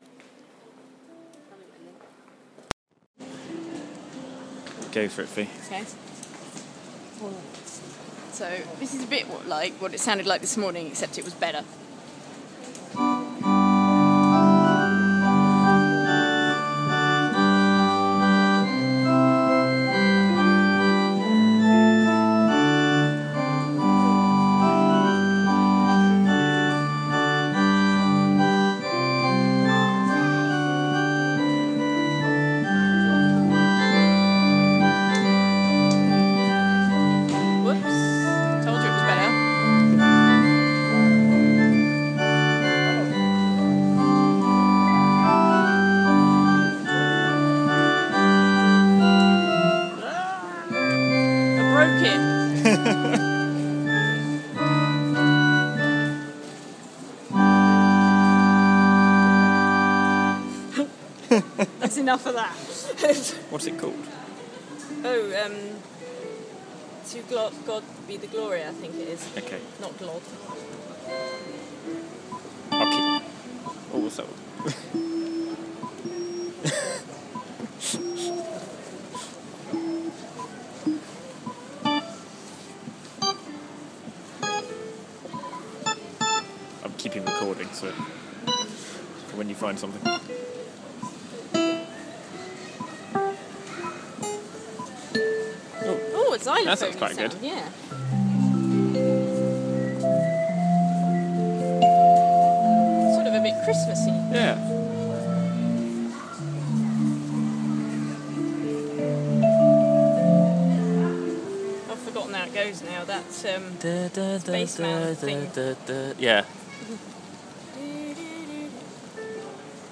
playing about on a piano in the Crowngate Shopping Centre